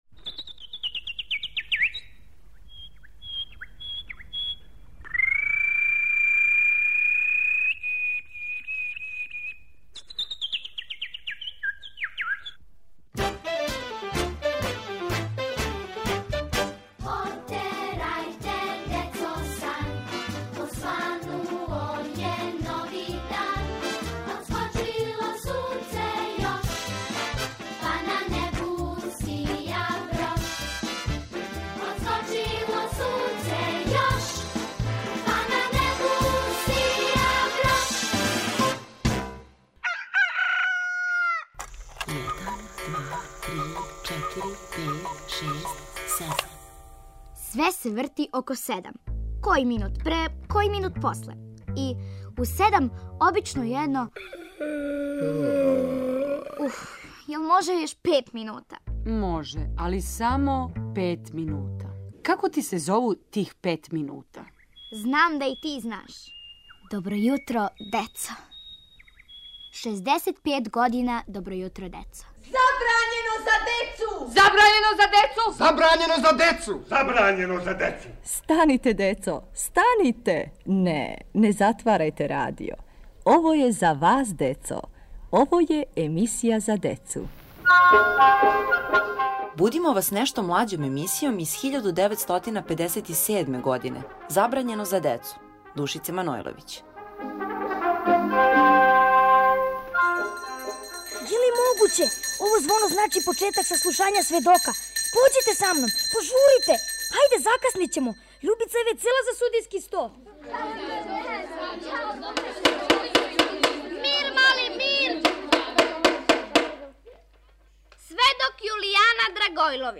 Поводом 65. рођендана емисије "Добро јутро децо" будимо вас емисијом из старе фиоке "Забрањено за децу", из 1957. године, ауторке Душице Манојловић.